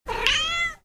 Cat Meow 7 Bouton sonore